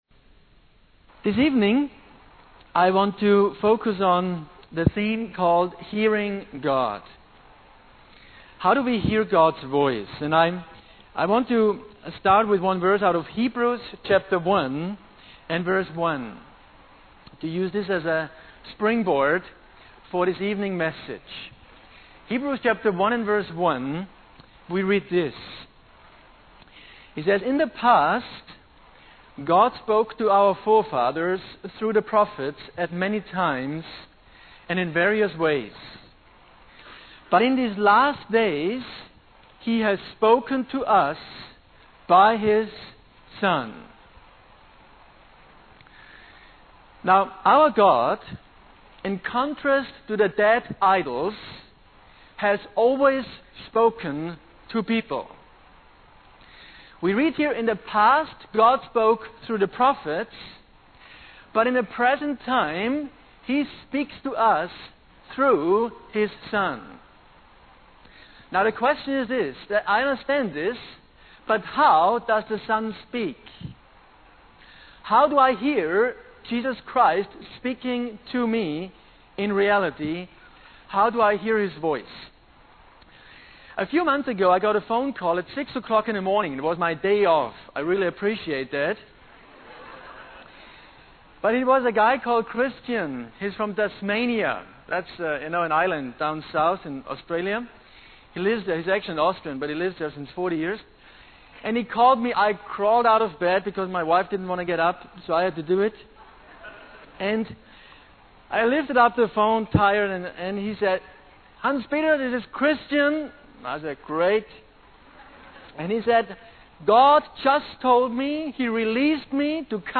In this sermon, the speaker discusses how to determine if a message is from God. They emphasize that the content of a word from God will always align with the general revelation found in the Scriptures.